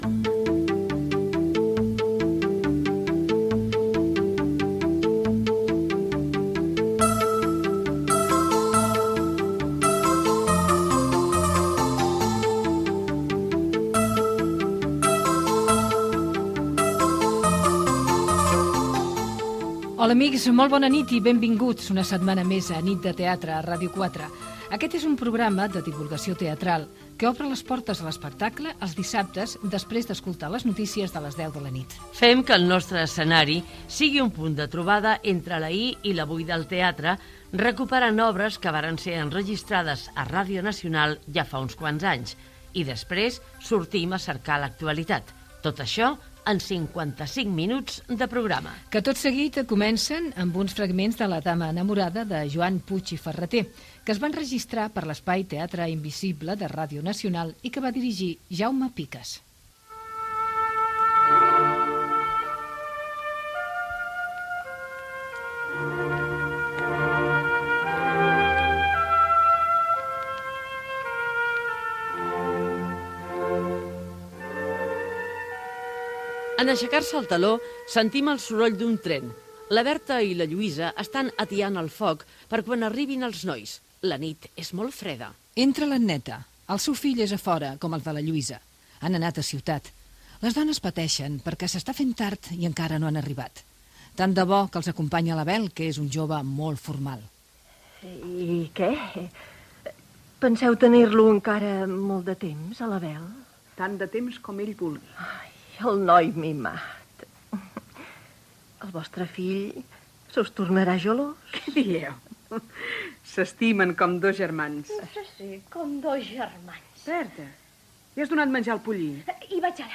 versió radiofònica